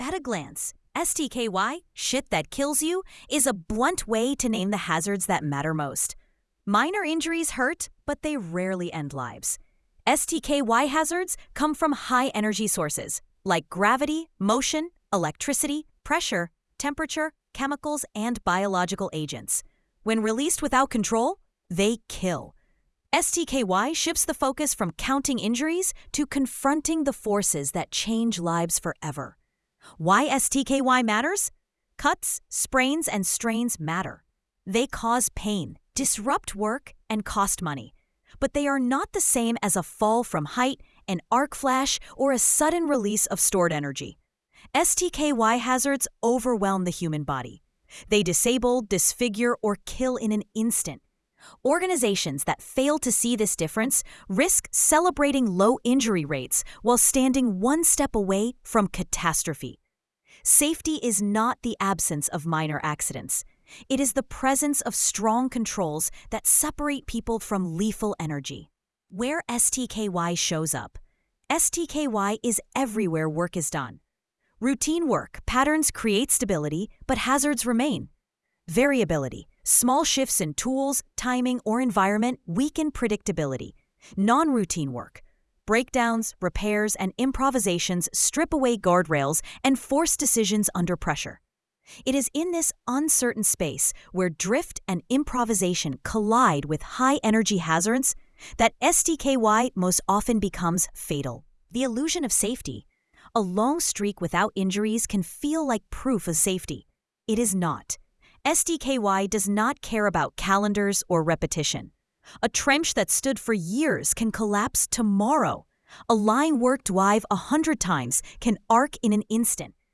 sage_gpt-4o-mini-tts_1x_2025-10-01T20_36_37-183Z.wav